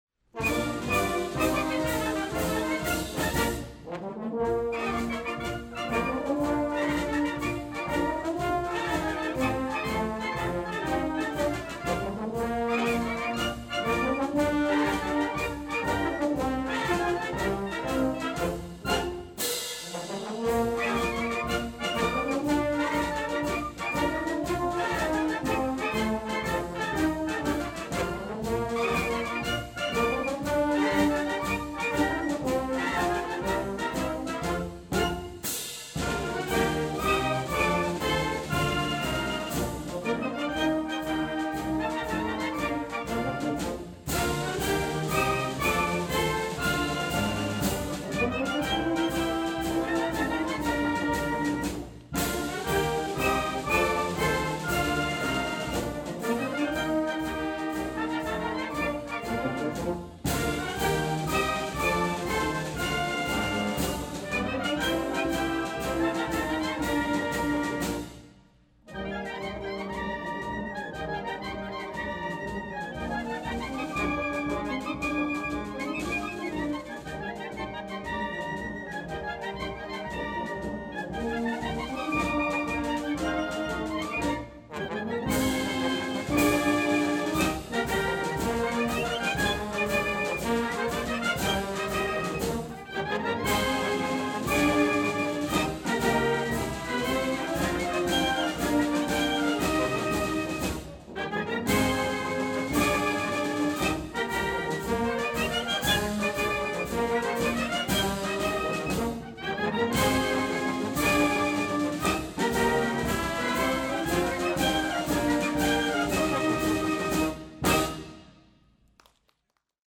WJU’s Summer Meet and 50th Annual Convention Concert Circus Band played its finale public concert in the Oasis Hotel & Convention Center’s Grand Ballroom on July 17, 2022.